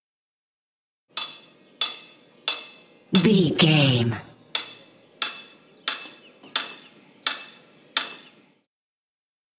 Construction ambience hit metal single
Sound Effects
urban
ambience